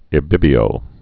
(ĭ-bĭbē-ō)